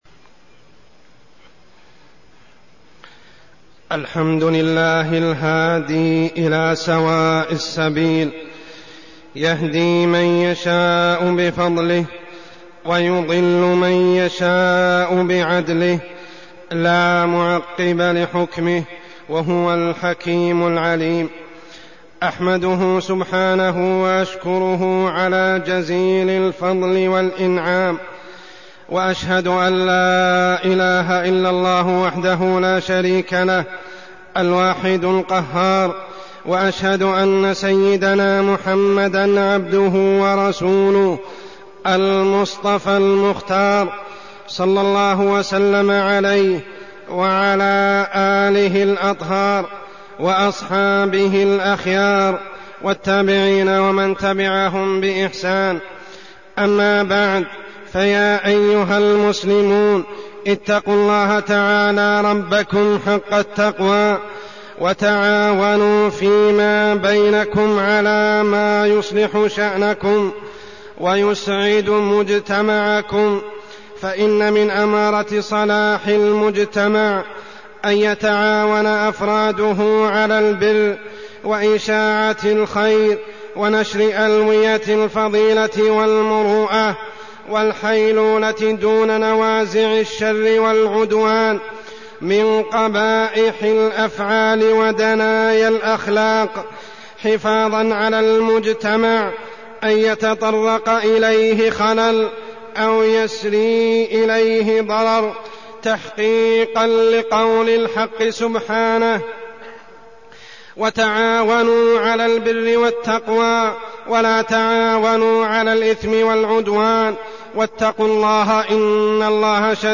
تاريخ النشر ٢٩ جمادى الأولى ١٤١٧ هـ المكان: المسجد الحرام الشيخ: عمر السبيل عمر السبيل تعاطي الرشوة